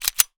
gun_pistol_cock_02.wav